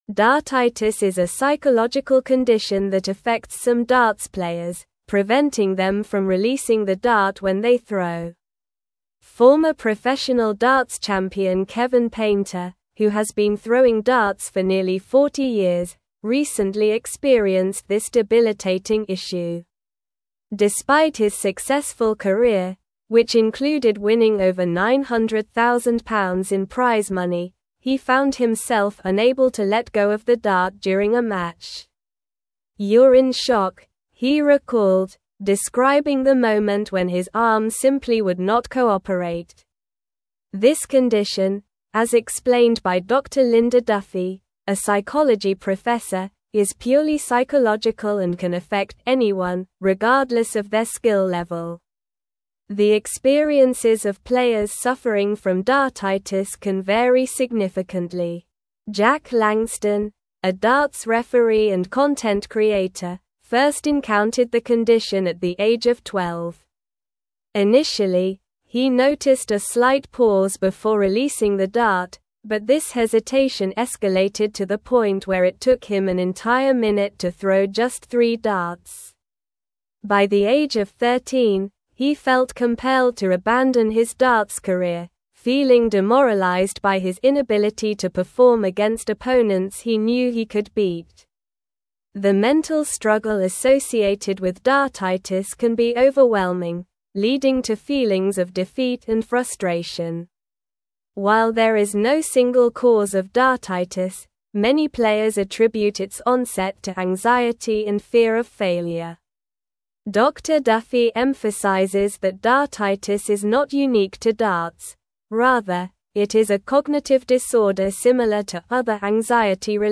Slow
English-Newsroom-Advanced-SLOW-Reading-Overcoming-Dartitis-Athletes-Mental-Health-Challenges.mp3